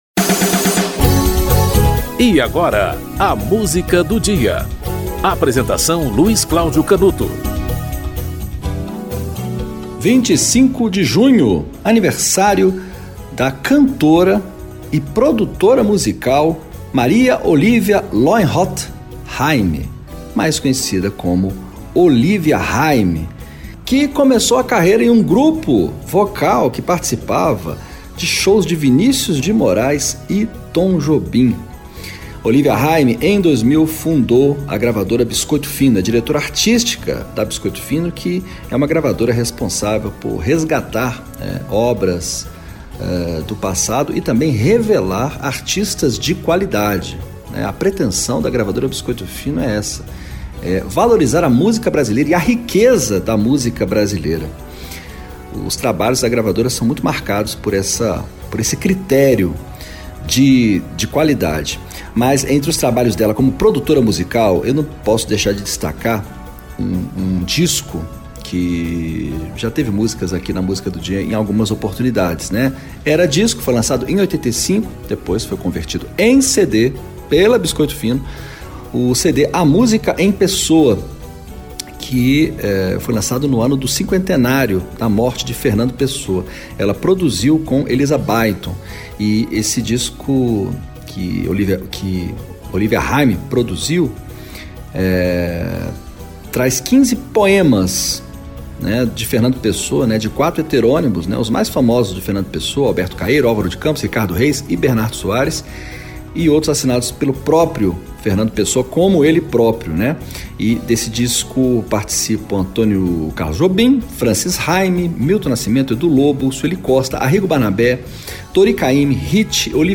Francis Hime e Olívia Hime - Glosa (Fernando Pessoa e Francis Hime)
O programa apresenta, diariamente, uma música para "ilustrar" um fato histórico ou curioso que ocorreu naquele dia ao longo da História.